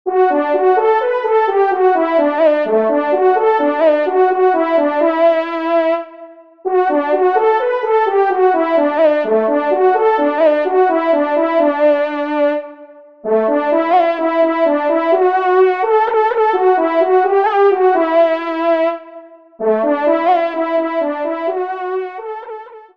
Solo Trompe (Ton de vénerie)
Rallye-Nogentaise_Ile-de-France_TDV_EXT.mp3